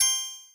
Coins (7).wav